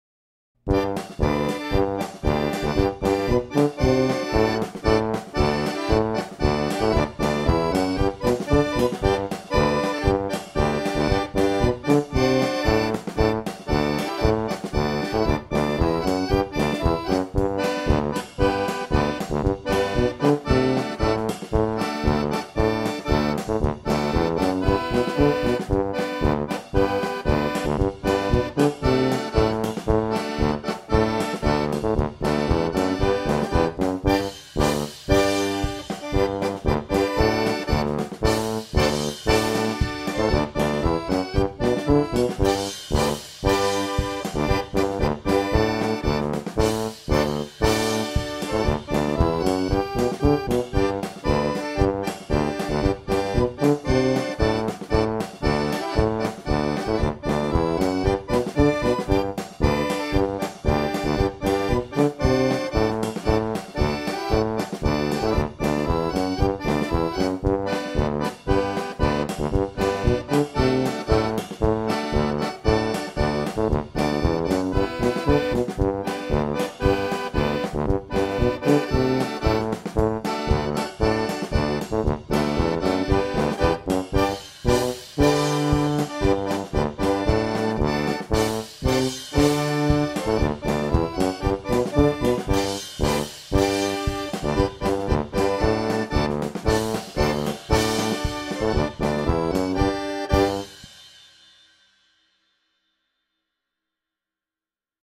Dvorak_Polka.mp3